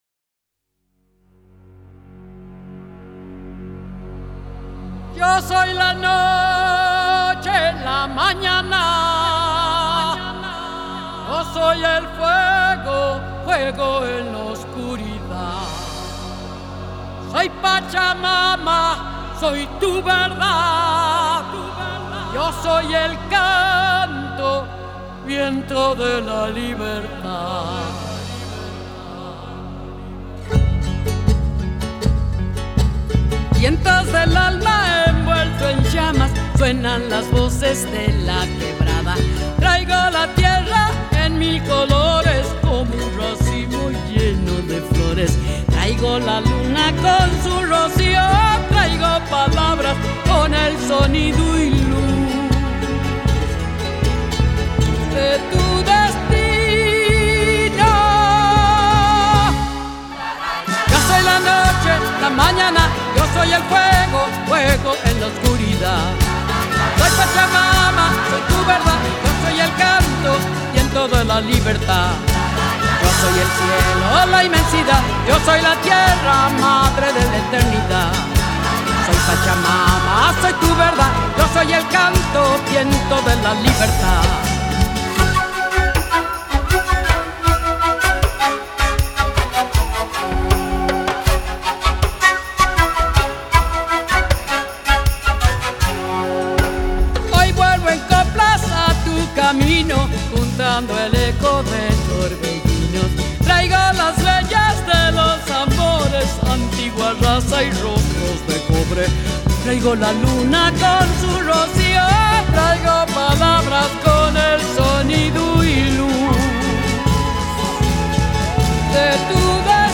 Жанр: Ethnic